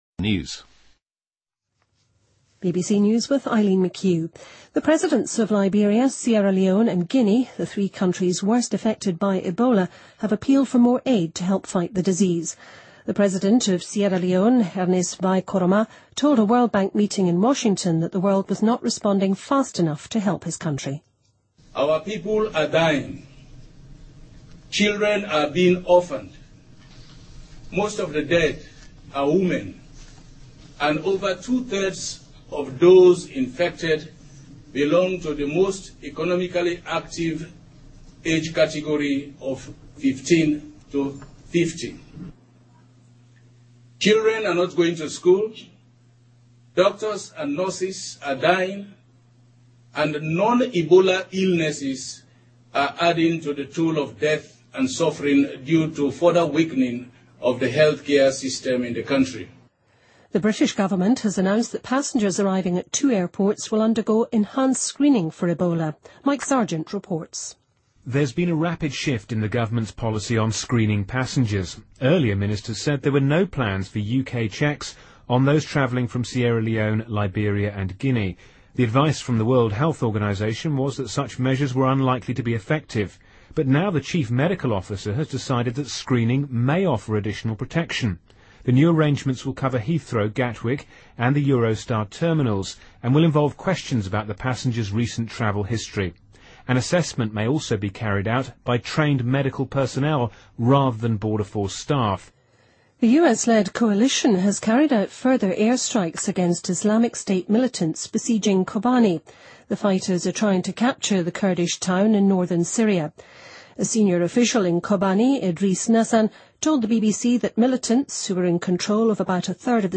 BBC news,网上零售商店巨商亚马逊将要开放其第一家实体店面